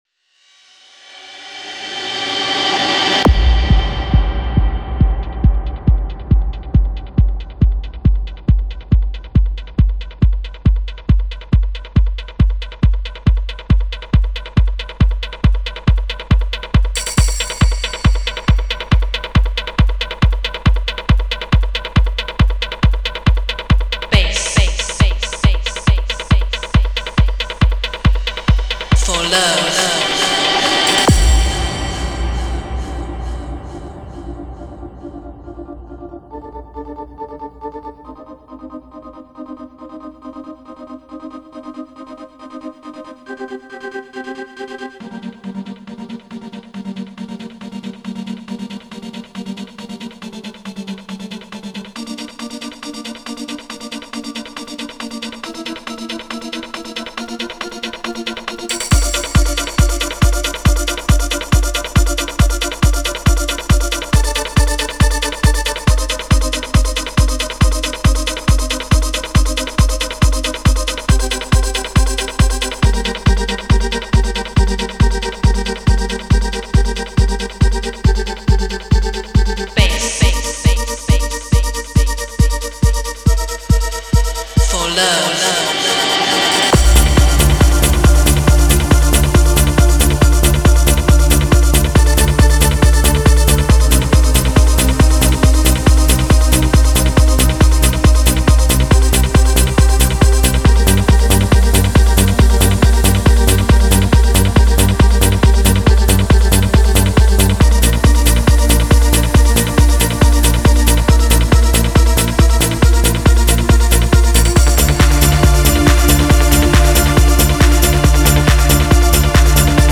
Genre: Trance.